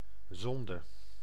Ääntäminen
Ääntäminen France: IPA: [pe.ʃe] Haettu sana löytyi näillä lähdekielillä: ranska Käännös Konteksti Ääninäyte Substantiivit 1. zonde {f} uskonto Suku: m .